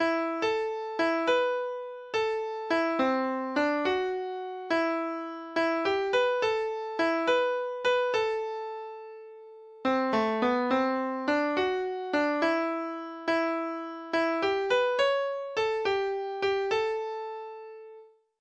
Lytt til data-generert lydfil